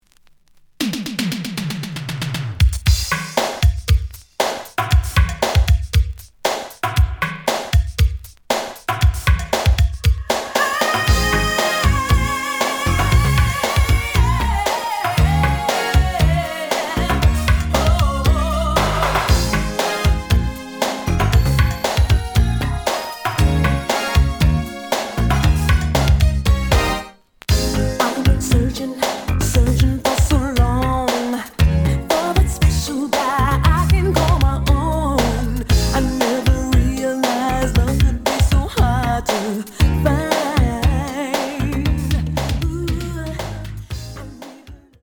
The audio sample is recorded from the actual item.
●Format: 7 inch
●Genre: Funk, 80's / 90's Funk